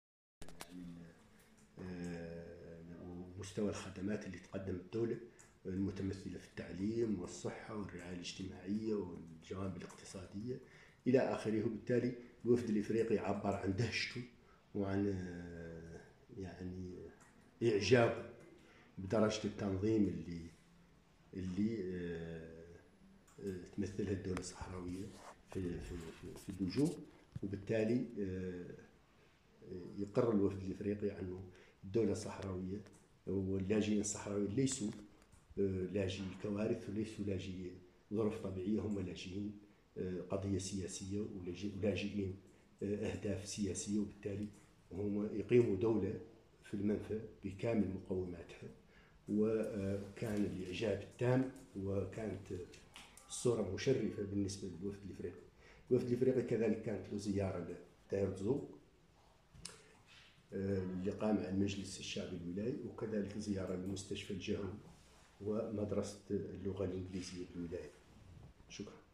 تصريح والي ولاية آوسرد محمد الشيخ محمد لحبيب بعد لقائه الوفد الإفريقي